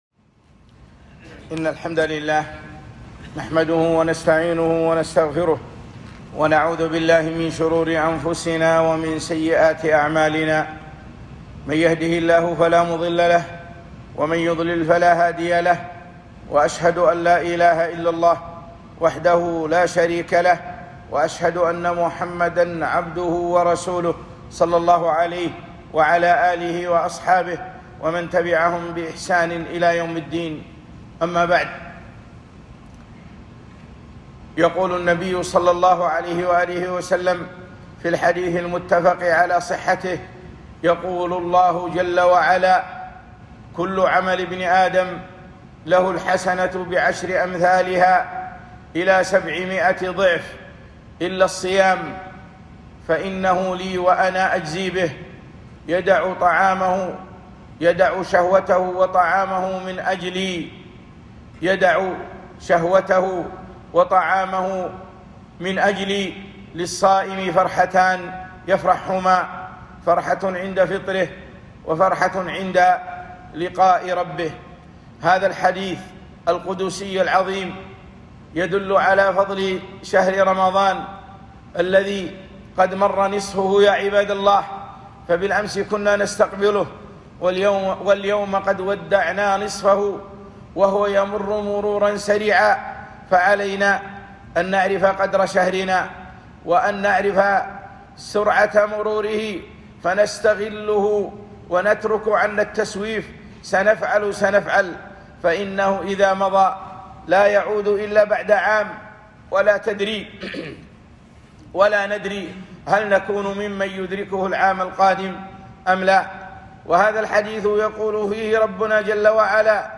خطبة - ها قد انتصف رمضان فشمروا يا عباد الله